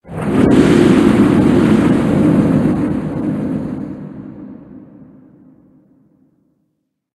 Cri de Scolocendre Gigamax dans Pokémon HOME.
Cri_0851_Gigamax_HOME.ogg